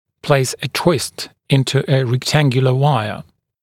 [pleɪs ə twɪst ‘ɪntə ə rek’tæŋgjələ ‘waɪə][плэйс э туист ‘интэ э рэк’тэнгйэлэ ‘уайэ]сделать продольный изгиб в прямоугольной дуге